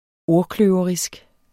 Udtale [ ˈoɐ̯ˌkløːvʌʁisg ]